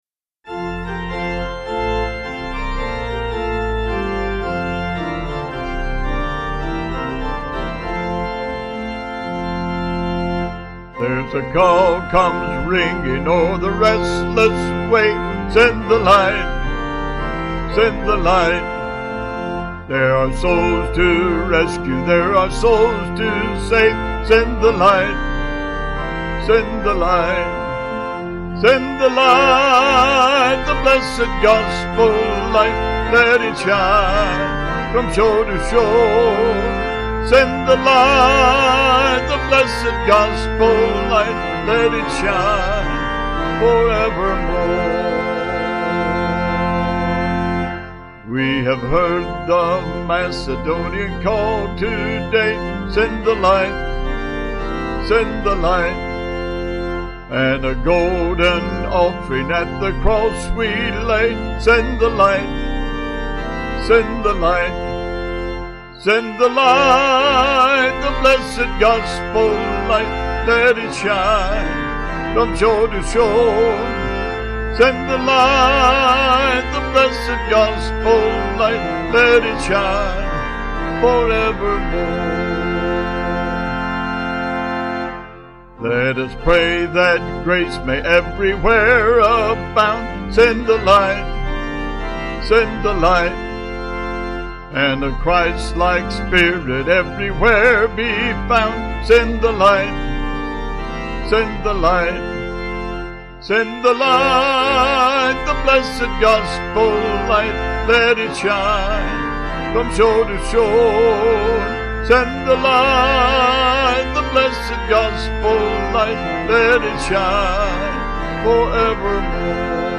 Vocals & Organ